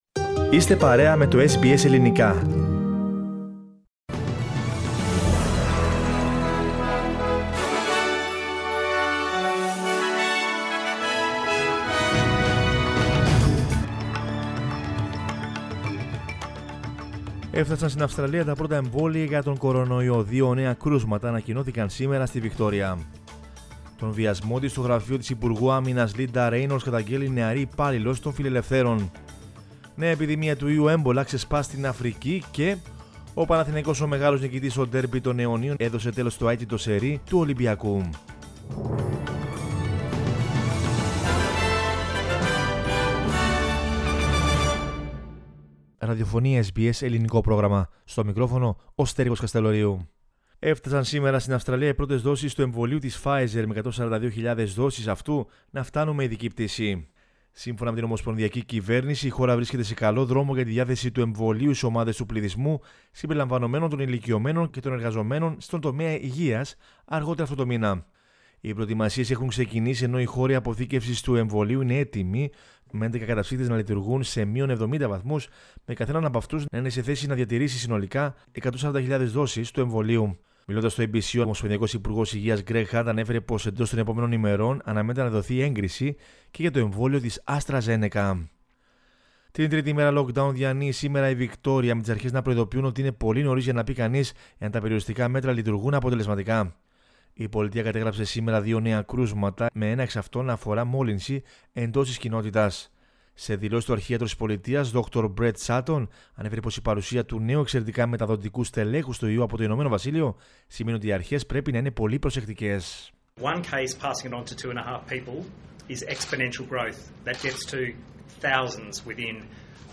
News in Greek from Australia, Greece, Cyprus and the world is the news bulletin of Monday 15 February 2021.